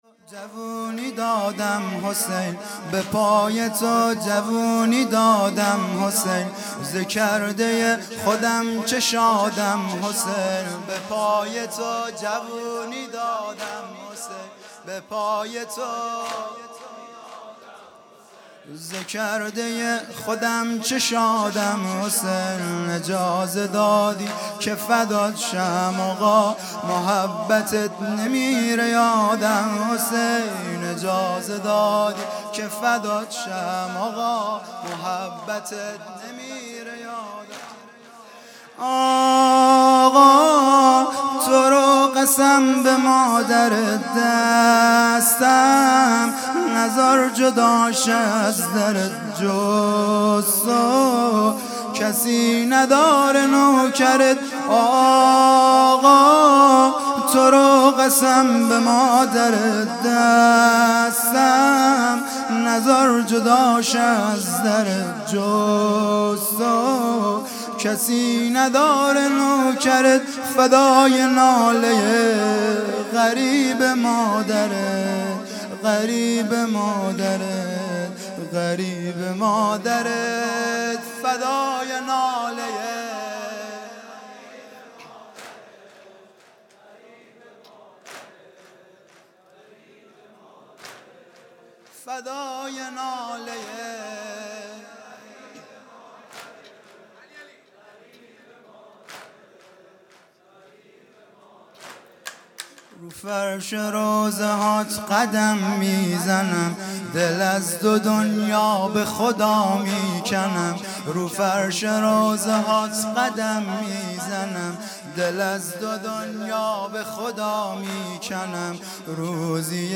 وفات حضرت زینب (س) | ۲۳ فروردین ۹۶